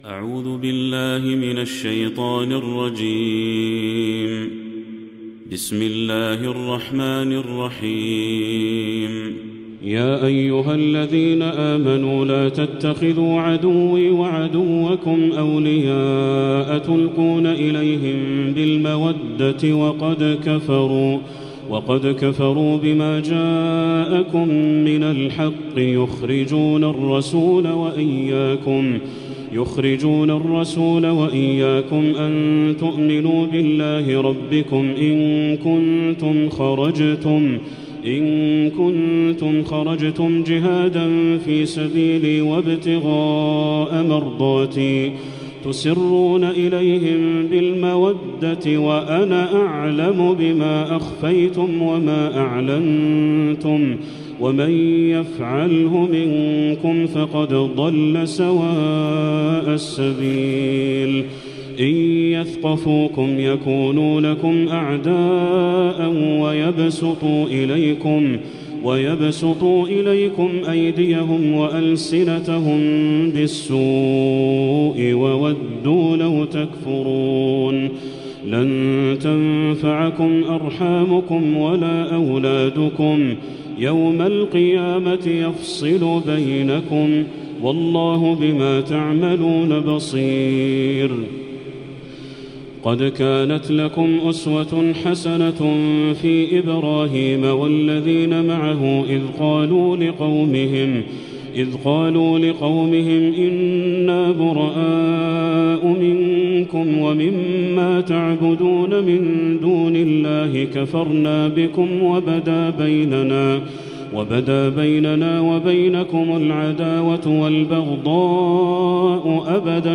تلاوات الحرمين